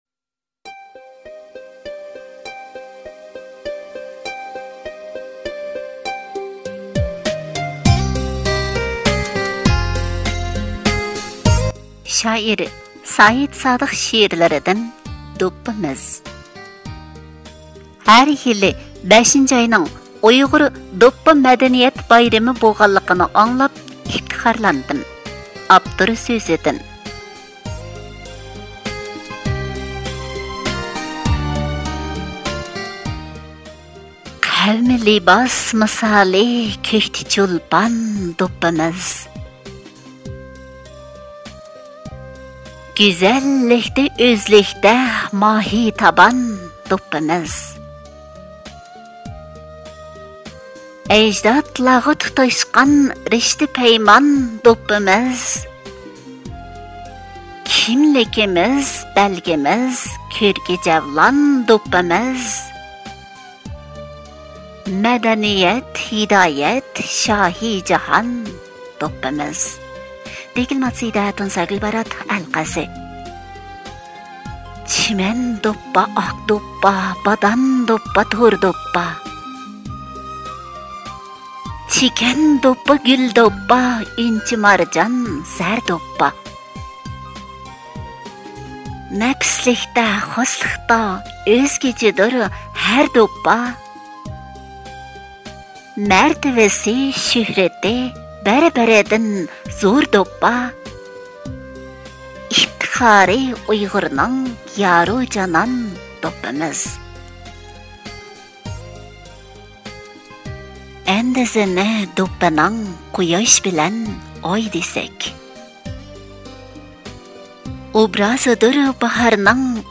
دوپپىمىز (ئاۋازلىق) ‹‹ئۇيغۇر دوپپا مەدەنىيەت بايرىمى›› غا ئاتاپ